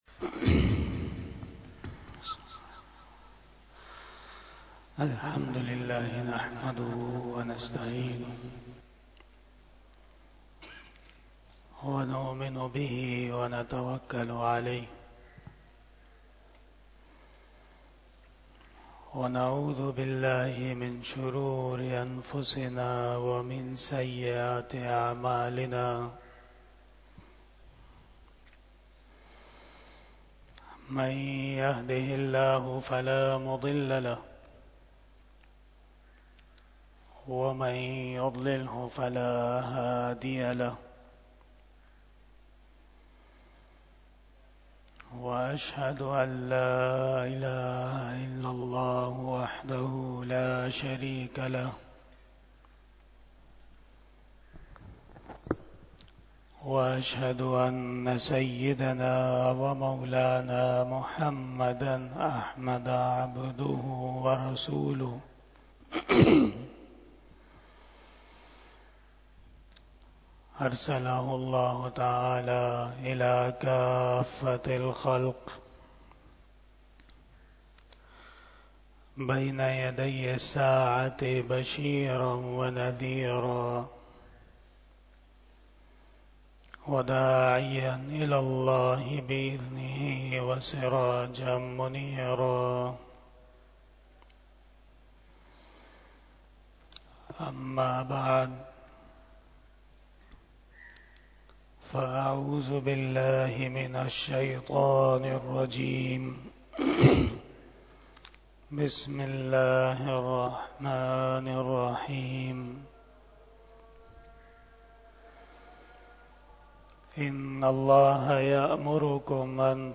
03 Bayan E Jummah 19 January 2024 (07 Rajab ul Murajab 1445 HJ)
Khitab-e-Jummah 2024